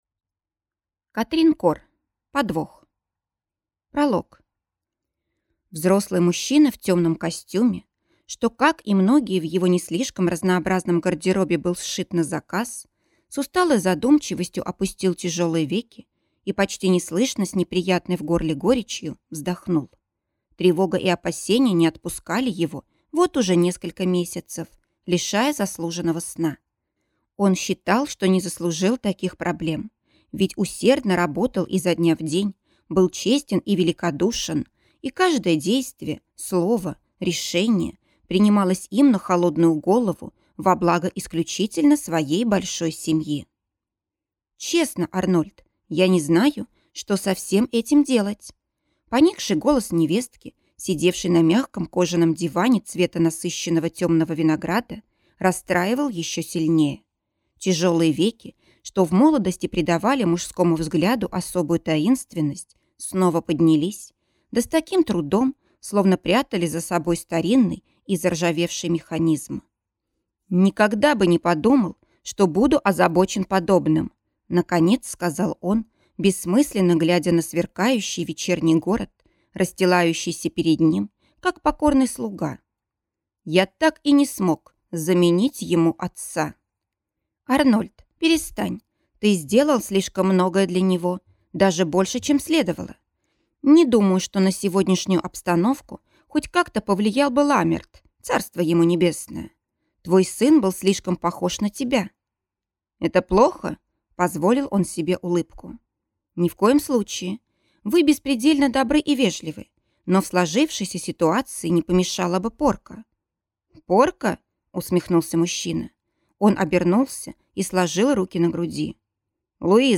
Аудиокнига Подвох | Библиотека аудиокниг